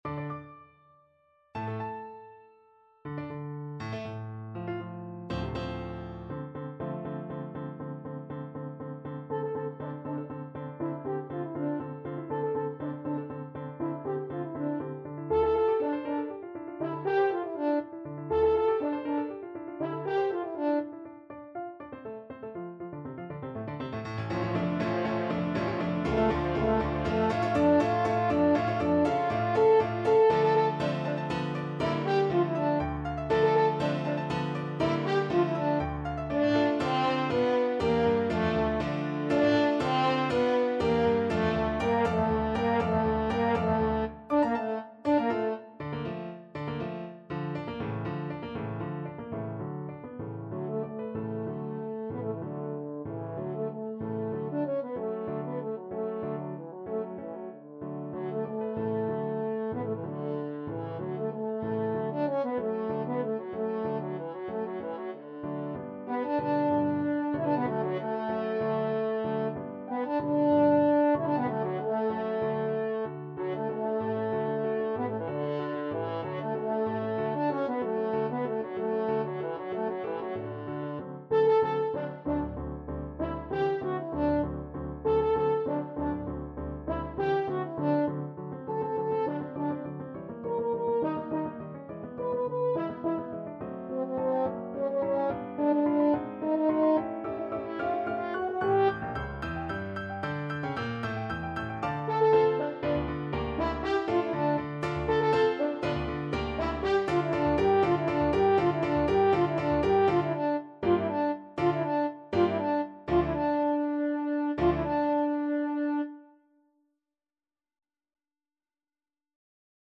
French Horn
3/4 (View more 3/4 Music)
D minor (Sounding Pitch) A minor (French Horn in F) (View more D minor Music for French Horn )
Molto vivace .=80
Classical (View more Classical French Horn Music)